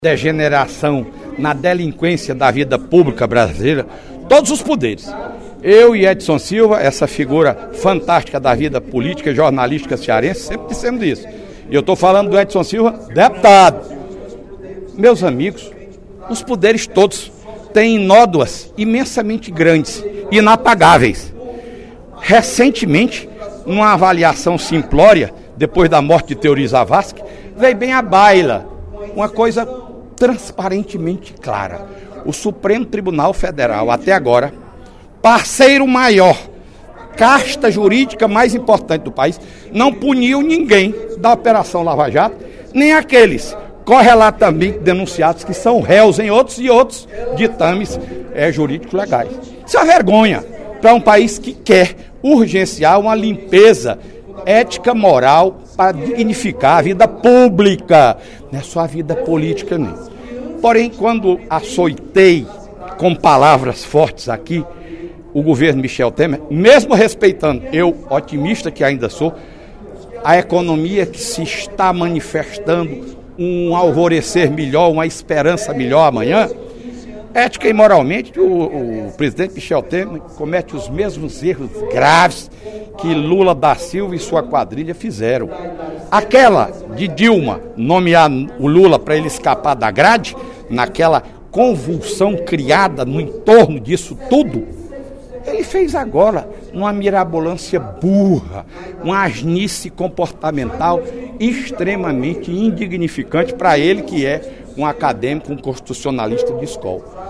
O deputado Fernando Hugo (PP) avaliou, durante o primeiro expediente da sessão plenária desta quinta-feira (09/02), o atual momento político do País.